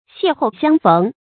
邂逅相逢 注音： ㄒㄧㄝ ˋ ㄏㄡˋ ㄒㄧㄤ ㄈㄥˊ 讀音讀法： 意思解釋： 見「邂逅相遇」。